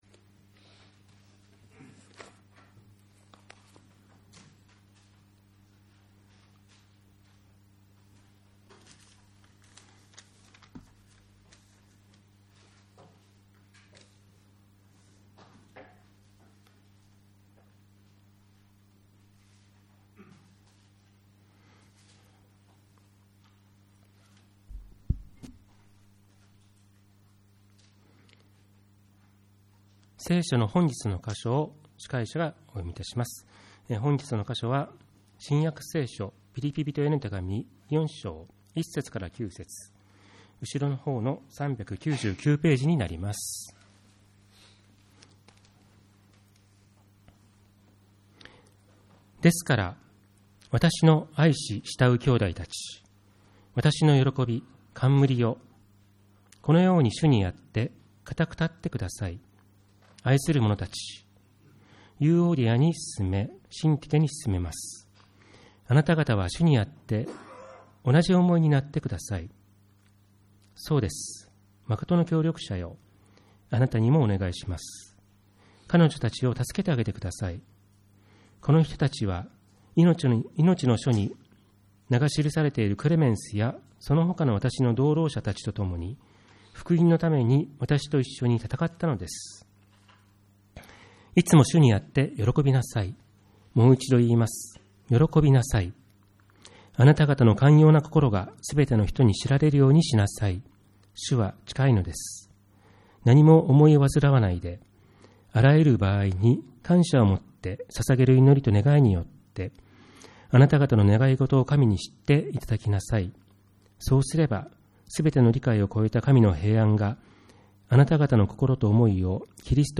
礼拝メッセージ